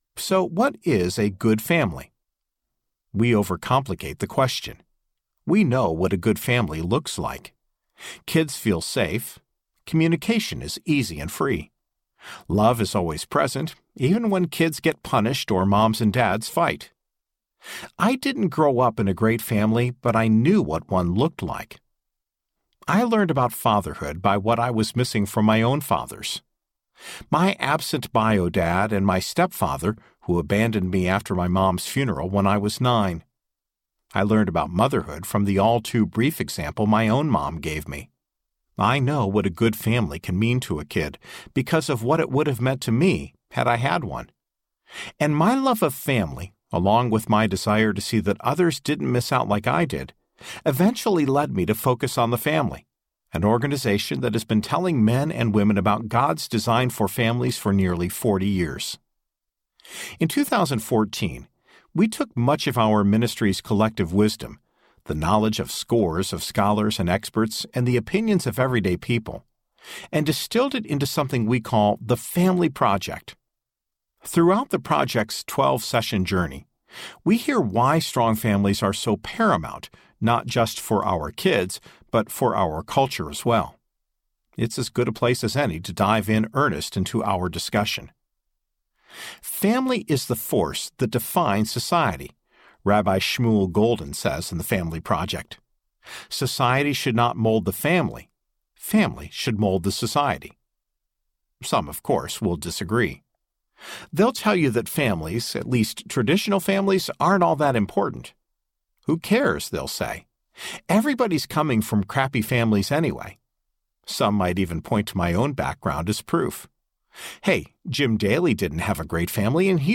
When Parenting Isn’t Perfect Audiobook
6.40 Hrs. – Unabridged